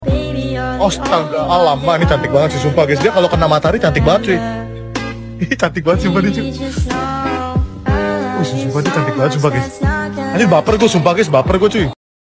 ya allah cantik banget Meme Sound Effect